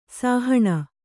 ♪ sāhaṇa